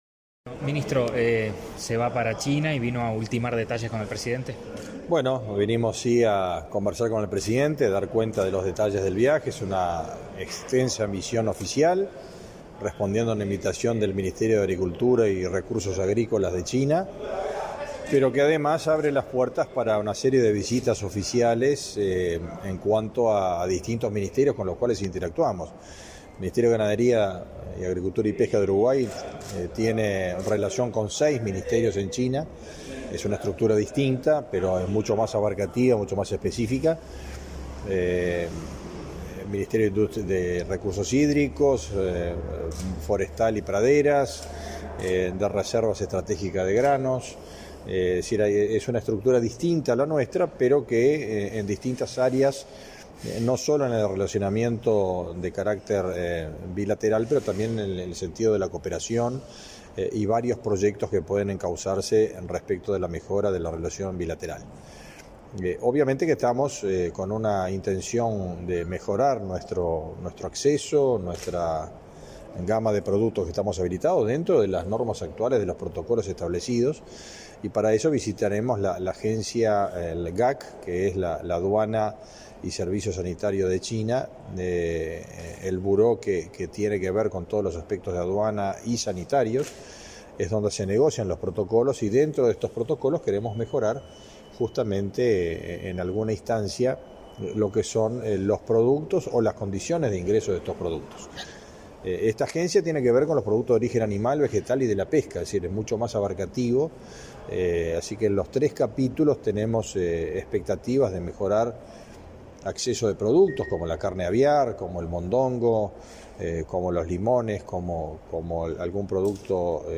Declaraciones a la prensa del ministro del MGAP, Fernando Mattos
Declaraciones a la prensa del ministro del MGAP, Fernando Mattos 26/04/2023 Compartir Facebook X Copiar enlace WhatsApp LinkedIn Tras reunirse con el presidente de la República, Luis Lacalle Pou, este 26 de abril, el titular del Ministerio de Ganadería, Agricultura y Pesca (MGAP), Fernando Mattos, realizó declaraciones a la prensa.